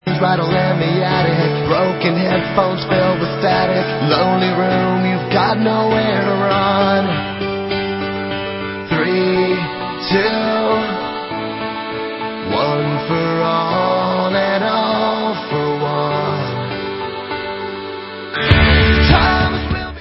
sledovat novinky v oddělení Alternative Rock
Rock